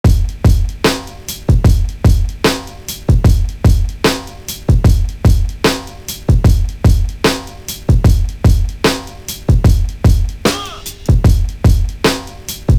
Drum Loop 4.wav